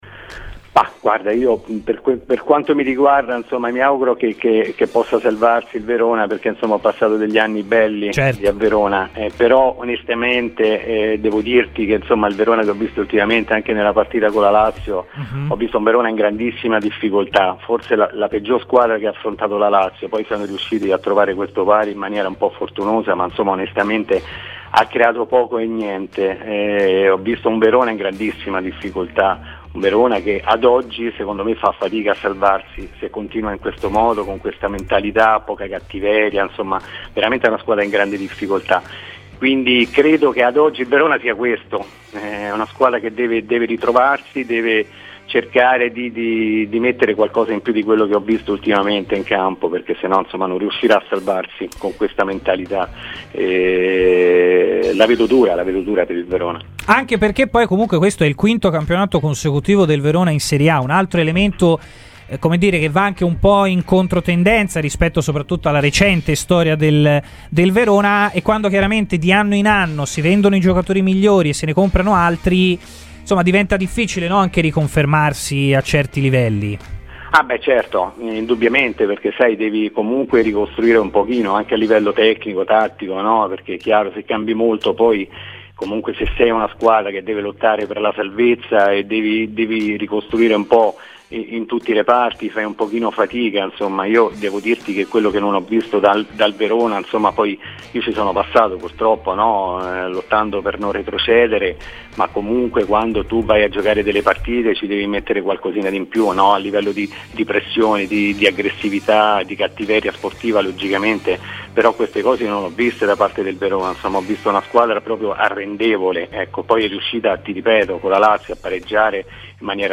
è intervenuto a "Firenze in campo" a Radio Firenzeviola per parlare della sfida di domani